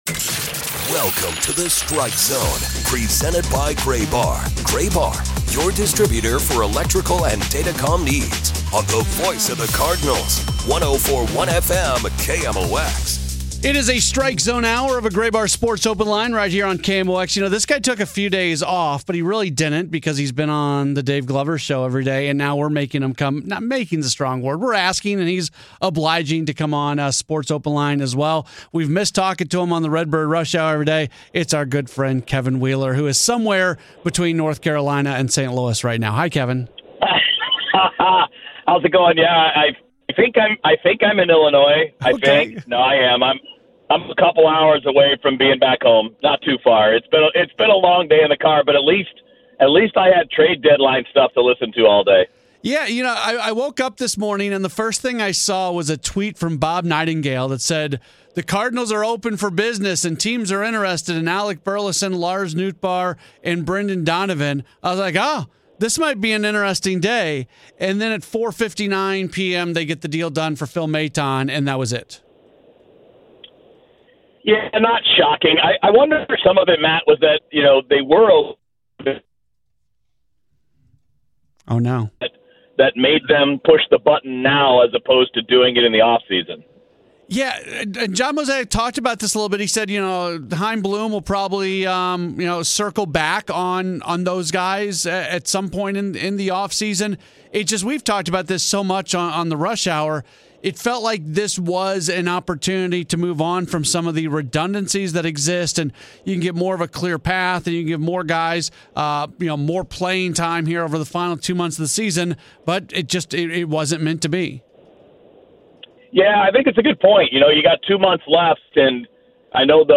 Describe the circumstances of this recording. The nightly program has been a fixture on KMOX for many years and features a variety of hosts.